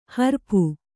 ♪ harpu